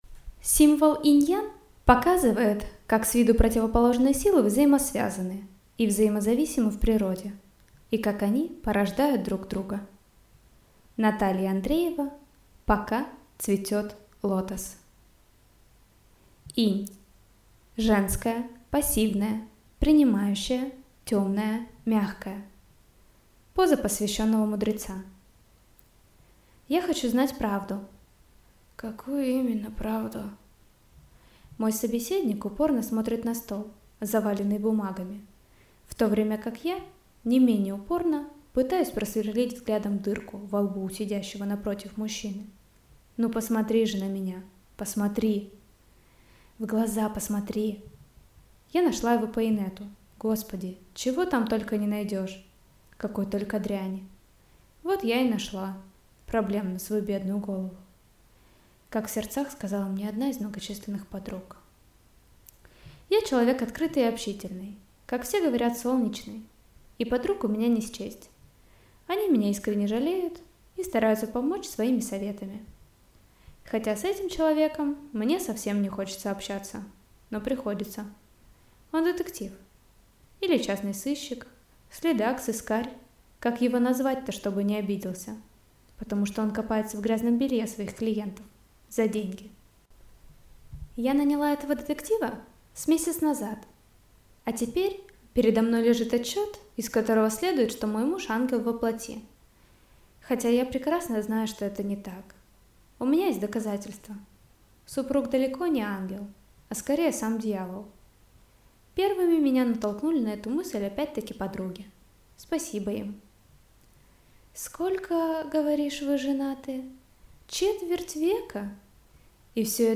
Аудиокнига Пока цветет лотос - купить, скачать и слушать онлайн | КнигоПоиск